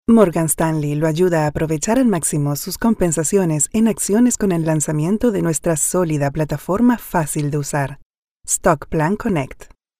0430EDS_PRESENTACIONPRODUCTO_MORGANSTANLEY.mp3